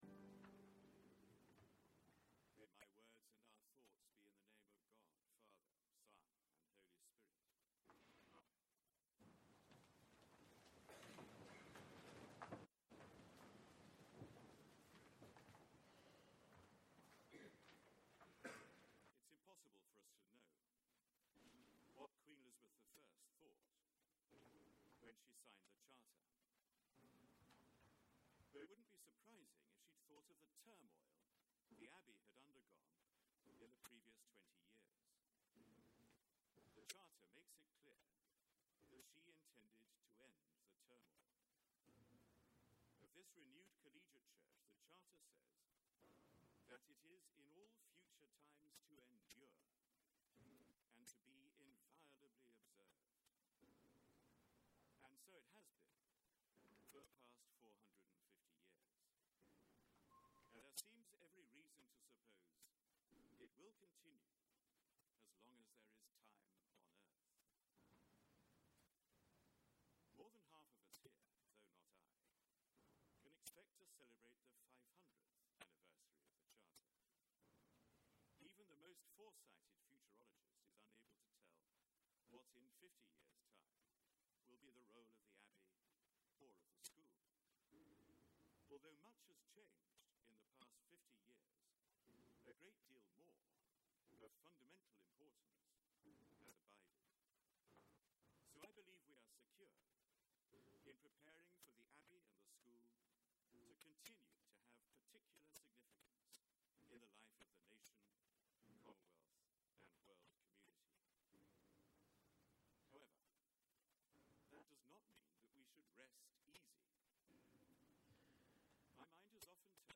Sermon given at a Service to Celebrate the 450th Anniversary of the Collegiate Foundation of St Peter: Friday 21 May 2010